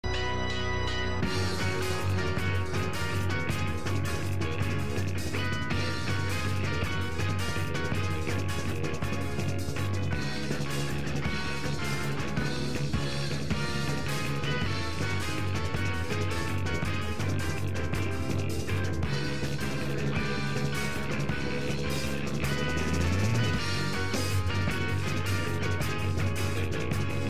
ウィーン少年合唱団だ、BGM だ、うっとうしいだと散々云われていたが、良質の BGM だと思って聴けばよいバンド。
BGM としては余りにも けたたましいが、メンバーが やりたい事をやっただけ。